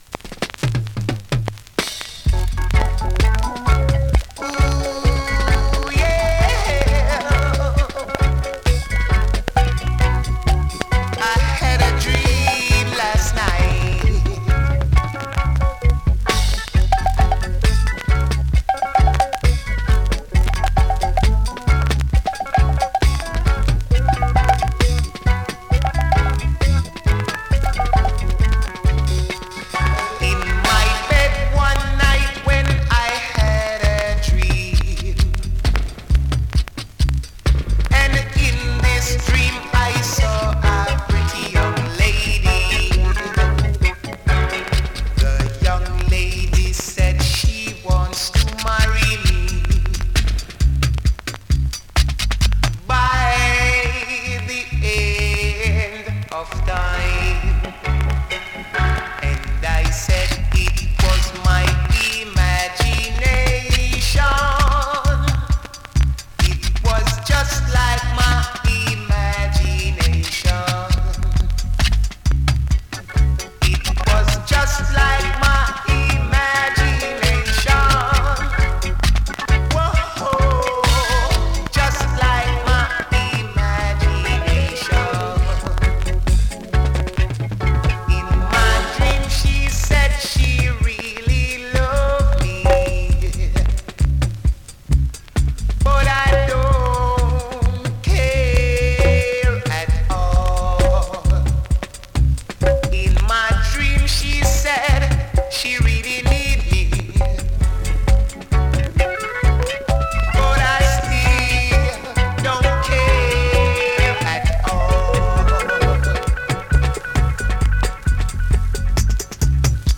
SKA〜REGGAE
スリキズ、ノイズそこそこあります。